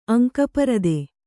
♪ aŋka parade